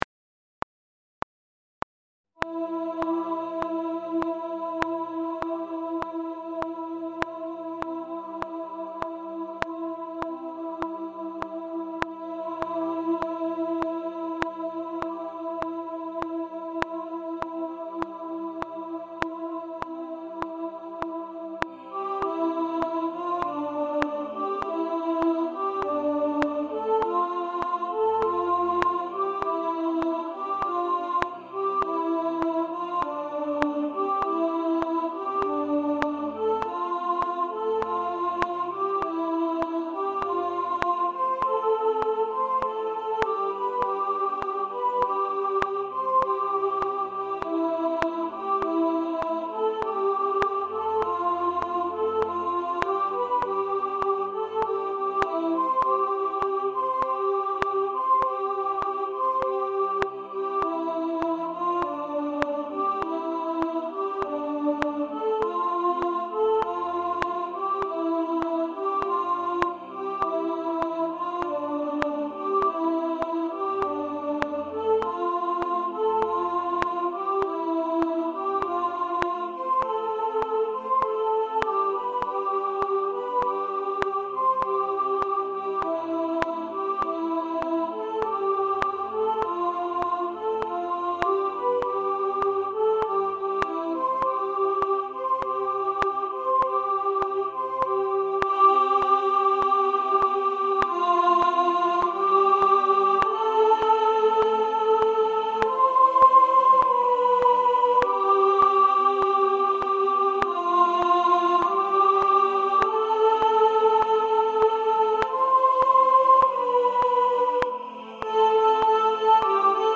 Only-You-Soprano-1.mp3